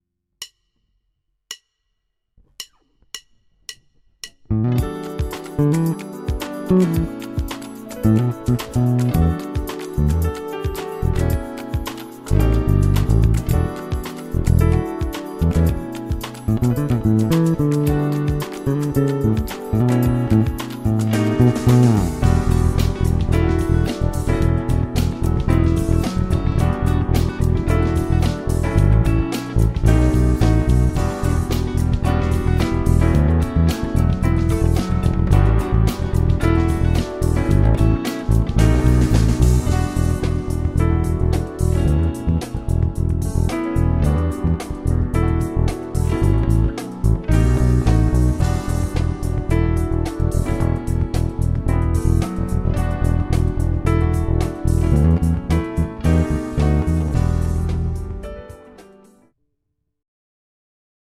Jedná se vzhledem o obyčejnou P bass, avšak s neobvyklou kombinací dřev a pár věcmi udělanými jinak.
Kdybych to měl popsat tak, jak slyším, tak to fakt hrne, ale opravdu moc příjemně smile.